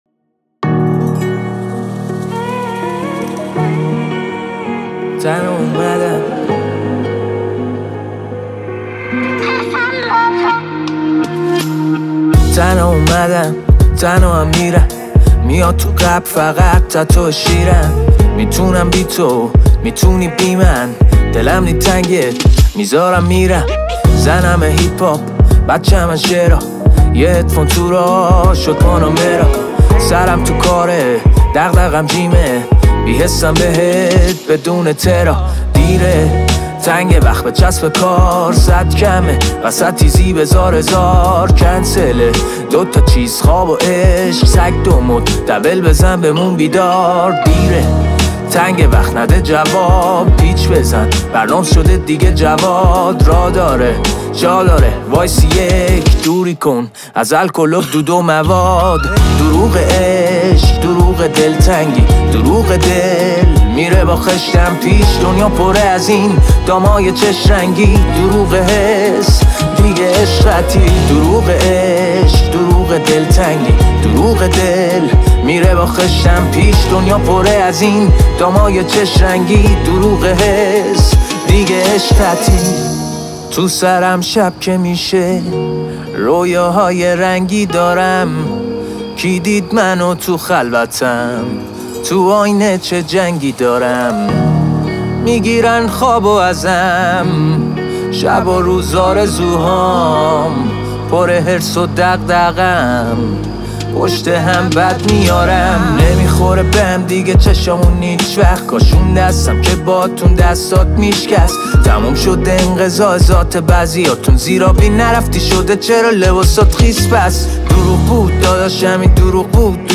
• موسیقی رپ ایرانی